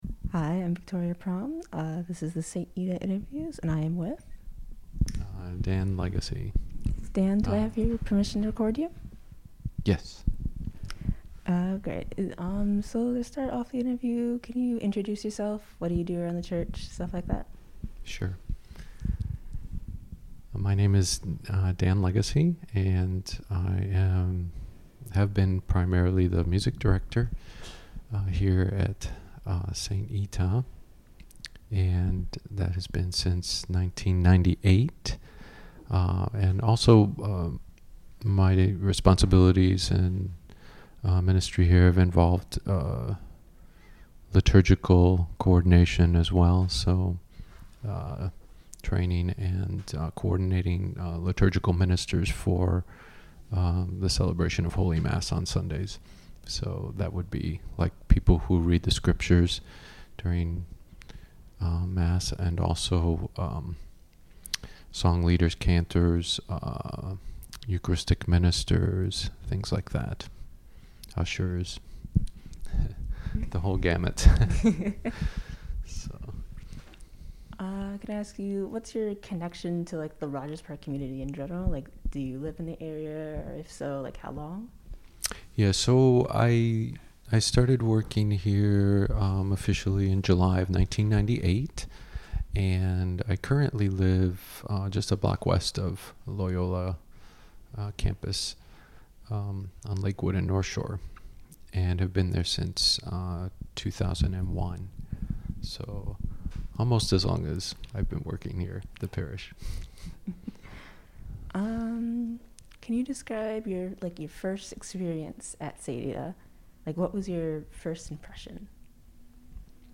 In this interview, we discussed his religious and musical career within the church, and how this has intersected with the Mary Mother of God merger. In addition, this interview gives an insight into community building within the church, and how the church’s history has impacted the merger.
It took place in person in the St. Ita Rectory.